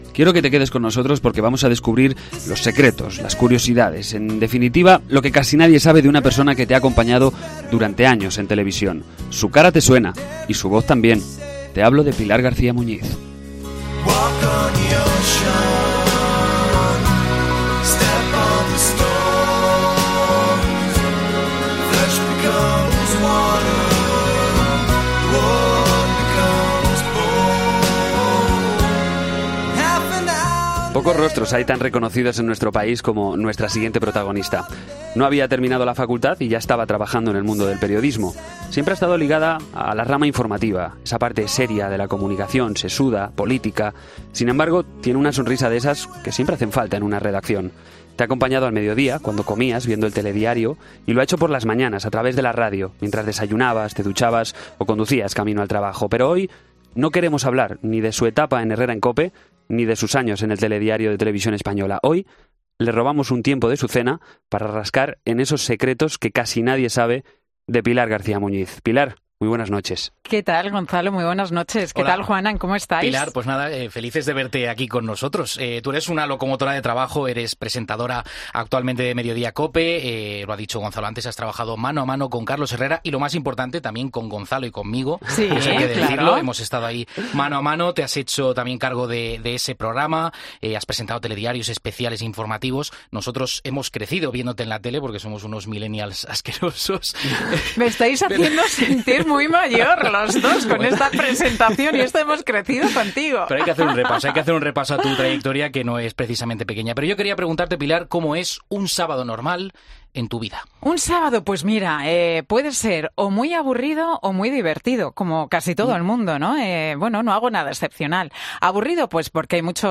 Esta chica es silbadora profesional.